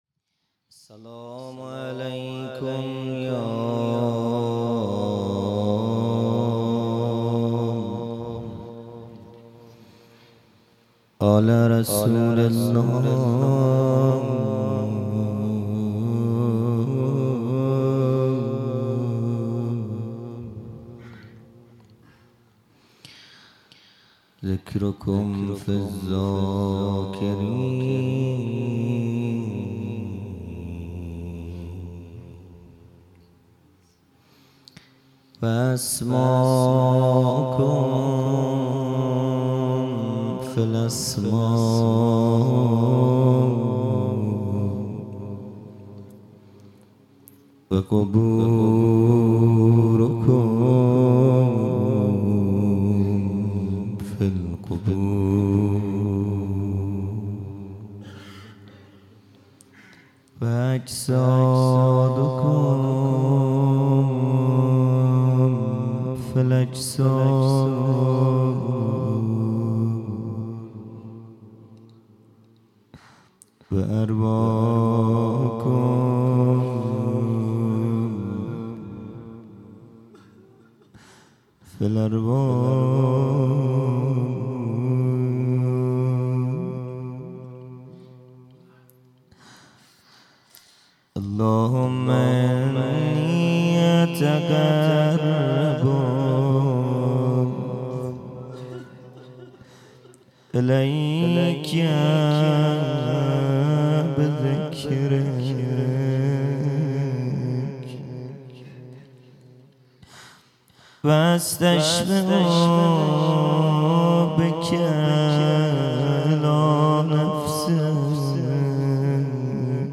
خیمه گاه - هیئت بچه های فاطمه (س) - روضه | قطع امید کرده ای از من حلال کن
جلسۀ هفتگی